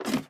ladder1.ogg